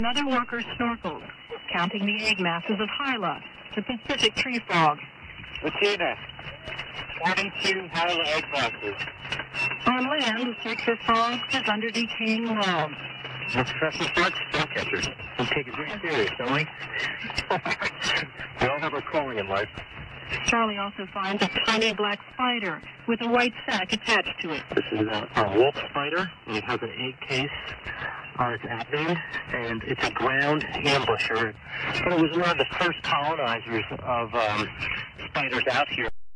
AMモードではフェーディングに伴い音が異様に割れると同時に音量が不自然に変わる。プツプツという人工的なノイズも発生。